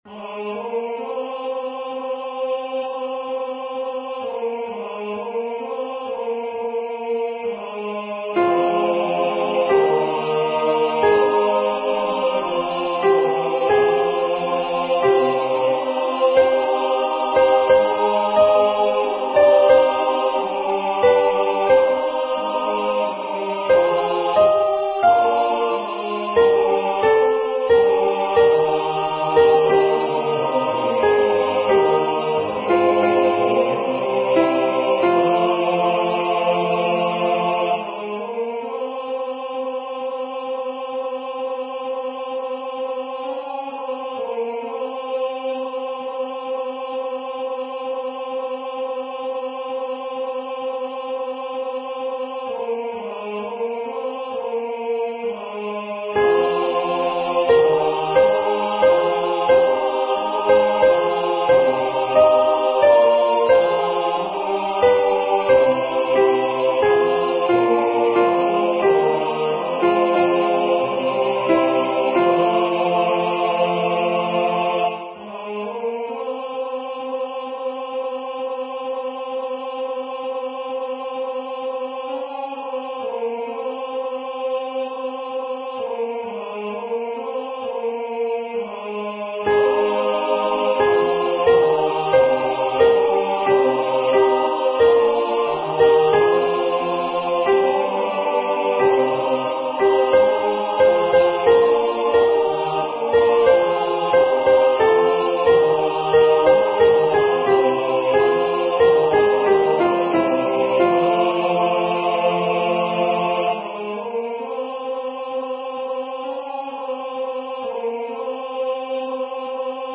with Accompaniment
MP3 Practice Files: Soprano:
Number of voices: 4vv   Voicing: SATB
Genre: SacredVespersEvening Canticles